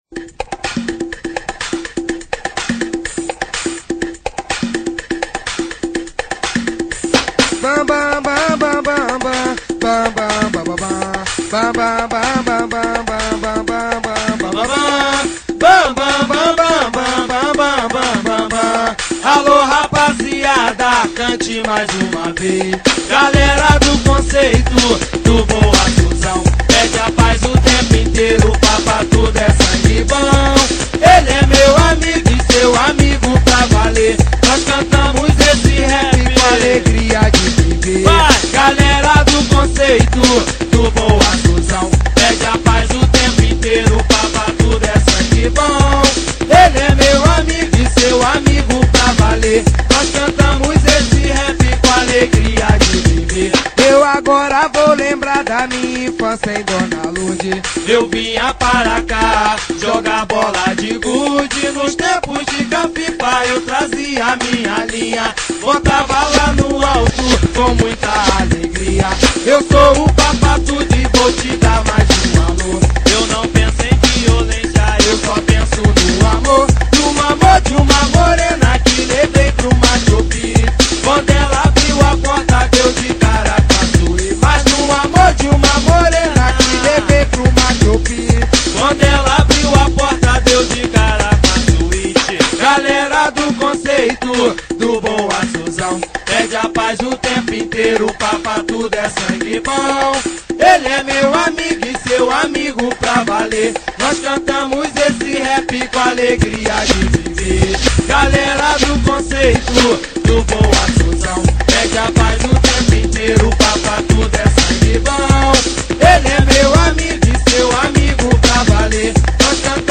Funk Para Ouvir: Clik na Musica.